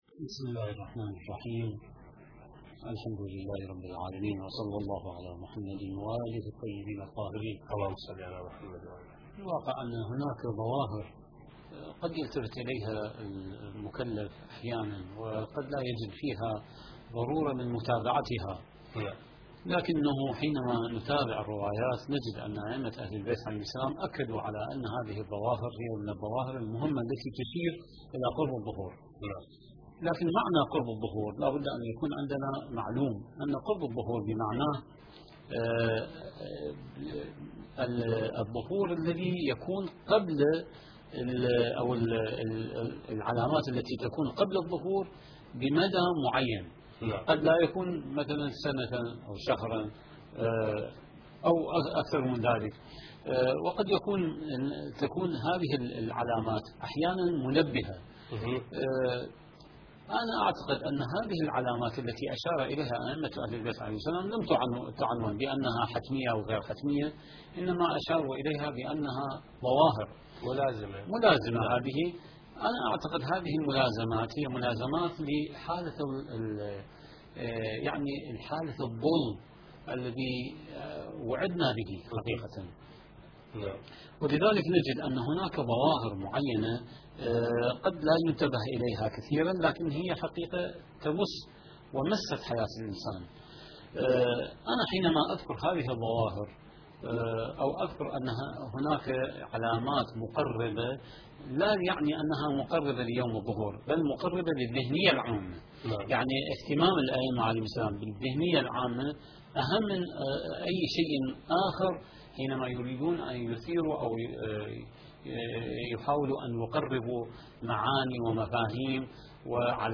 المكان: قناة كربلاء الفضائية